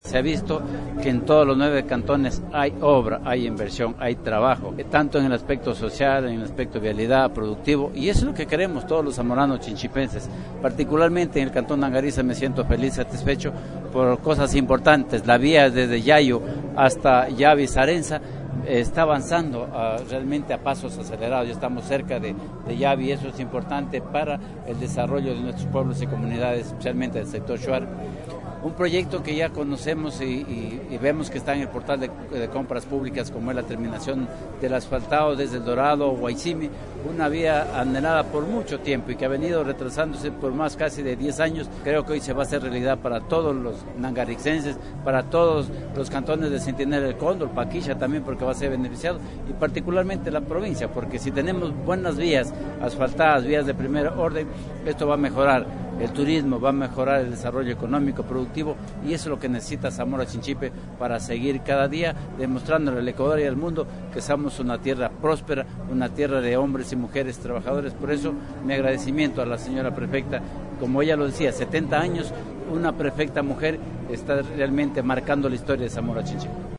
HABITANTE NANGARITZA